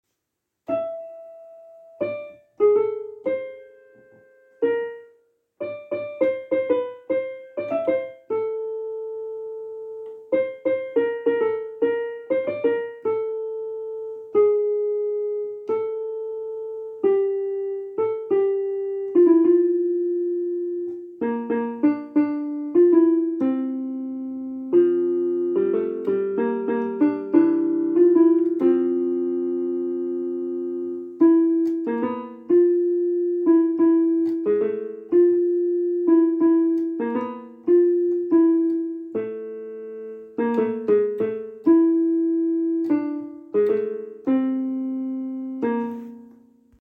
Очень известная инструменталка